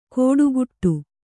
♪ kōḍuguṭṭuu